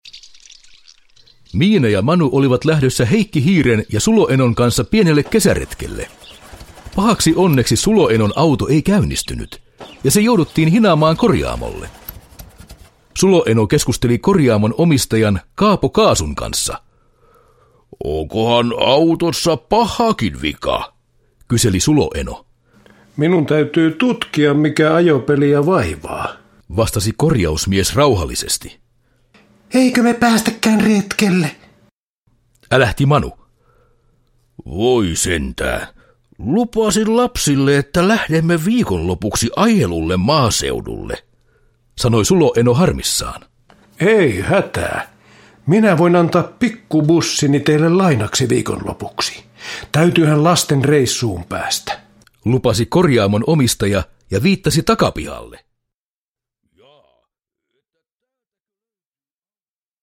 Miina ja Manu reissussa – Ljudbok – Laddas ner